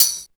59 TAMB.wav